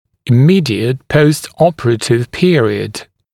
[ɪ’miːdɪət pəust-‘ɔpərətɪv ‘pɪərɪəd][и’ми:диэт поуст-‘опэрэтив ‘пиэриэд]период непосредственно после операции